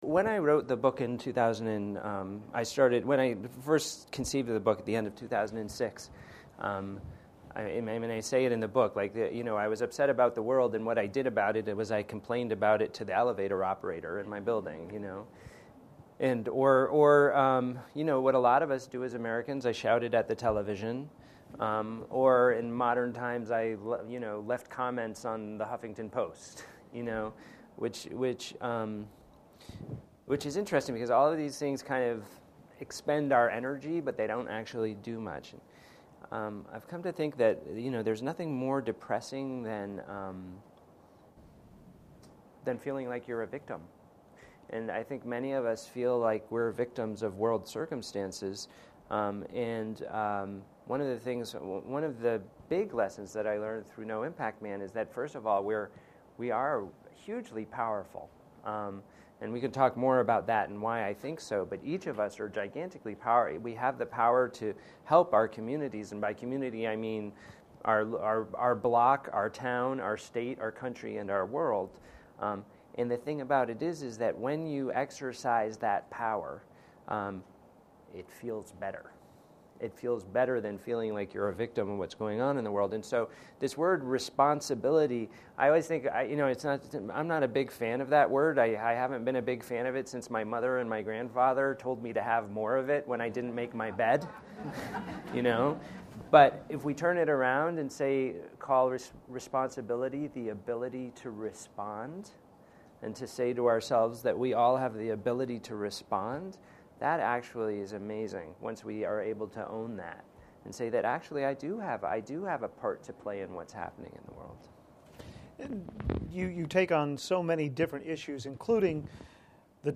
Colin Beavan At FPL’s Eighth Gathering of the Groups Colin_Beavan_FPL.mp3 The No Impact Man, during his visit to Fayetteville, was a part of a moderated discussion for the Eighth Gathering of the Groups at the Fayetteville Public Library.